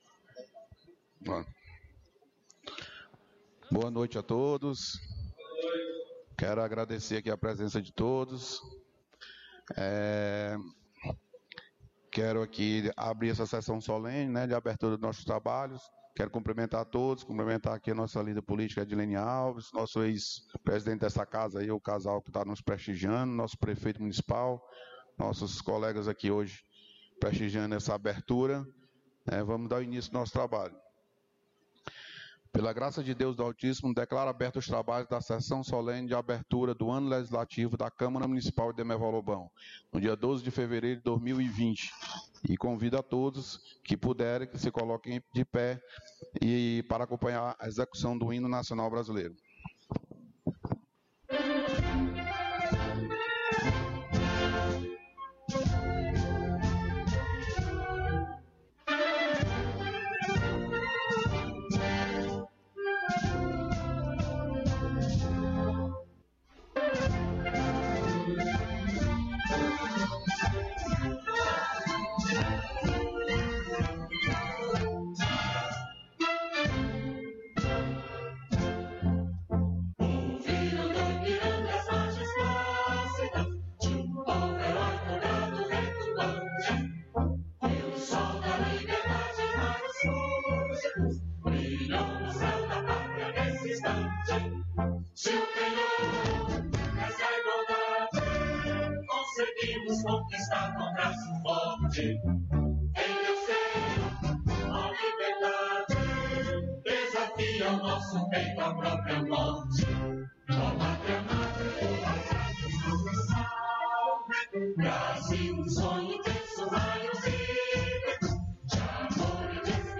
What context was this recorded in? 1ª Sessão Solene 12 de Fevereiro